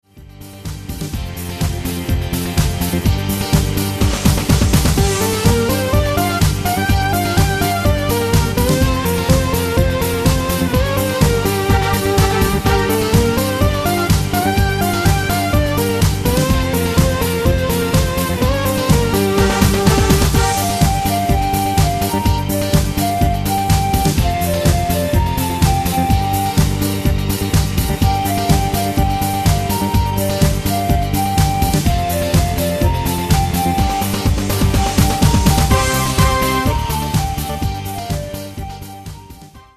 Žánr: Rock
Key: Gm